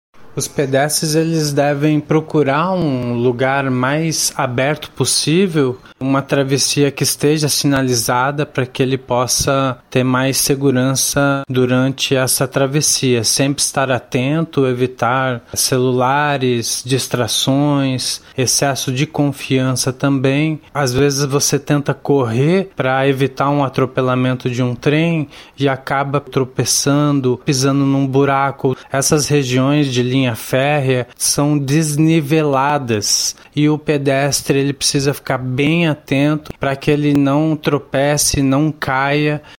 O que diz o especialista em Trânsito